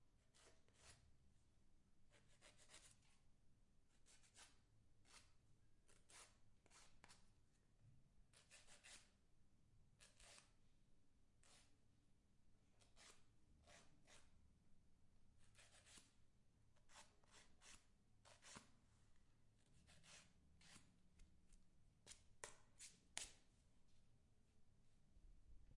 锉刀钉子
描述：钉子被提交变异。记录在Zoom H6上。
标签： 归档钉 硬效果 砂纸 以人的声音 砂纸 文件 美甲 文件管理器 金刚砂板 声音效果 FX 变焦H6 SFX 钉子 修指甲 修脚 美甲文件 人力 OWI 弗利 身体
声道立体声